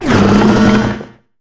Cri de Terraiste dans Pokémon HOME.